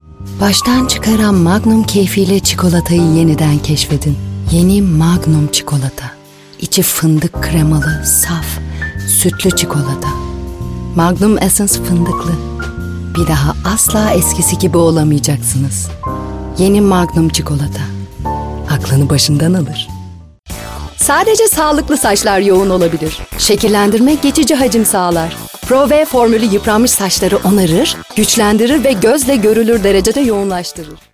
Middle Eastern, Turkish, Female, 20s-30s